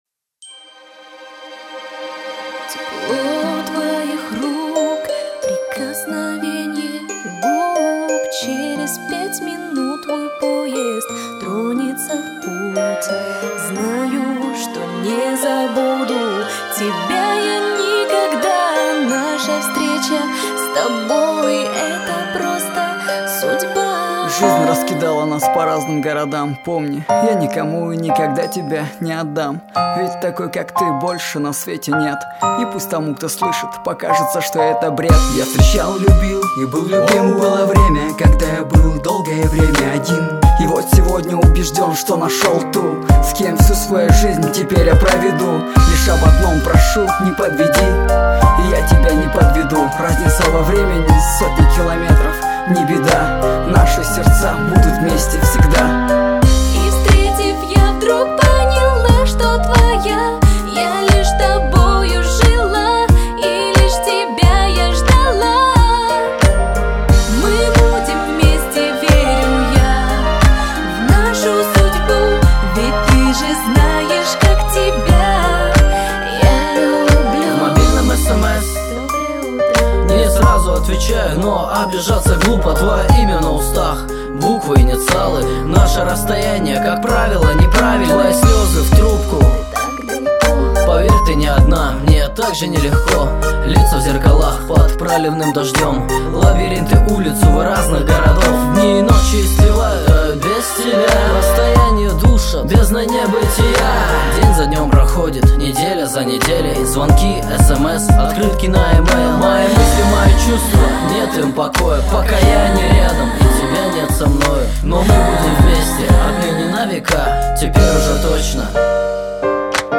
красиво_поет_девушка_-_любовь
krasivo_poet_devushka___ljybovjq.mp3